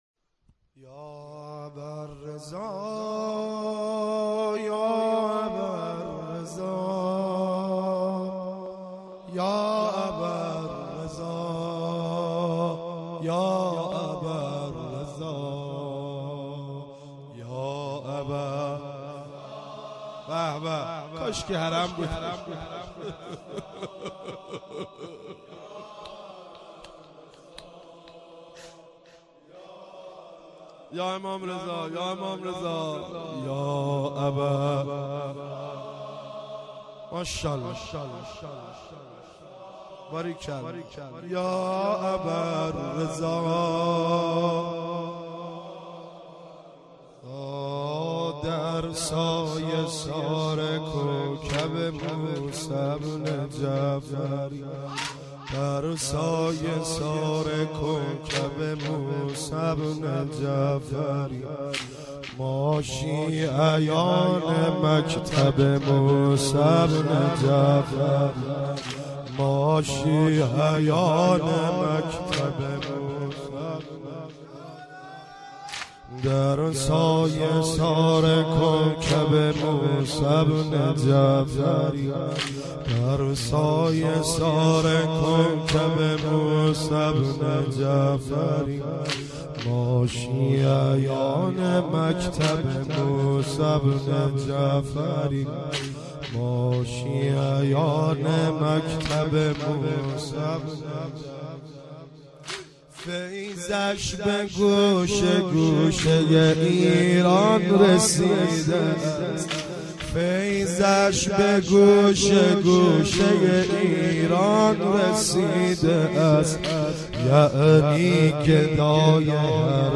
مداحی
بمناسبت شهادت امام موسی کاظم (ع)